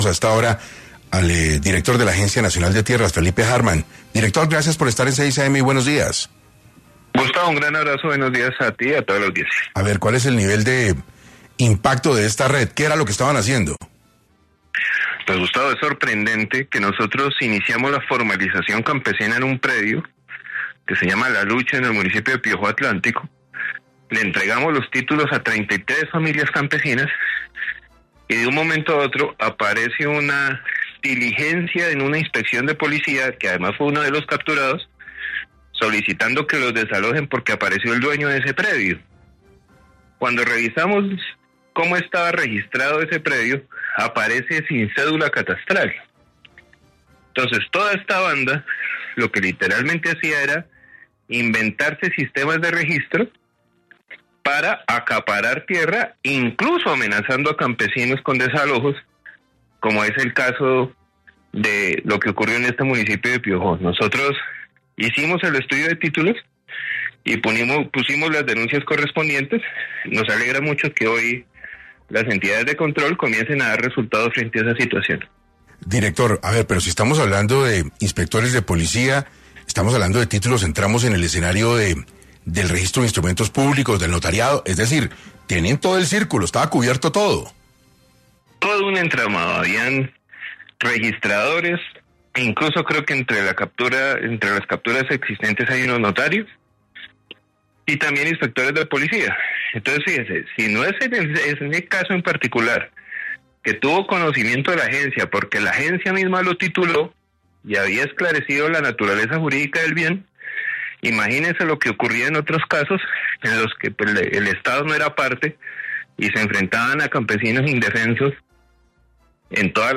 En entrevista con 6AM de Caracol Radio, Felipe Harman, director de la ANT explicó que los campesinos ubicados en el predio La Lucha, jurisdicción del municipio de Piojó (Atlántico), han sido objeto de reiterados intentos de desalojo por parte de terceros inescrupulosos.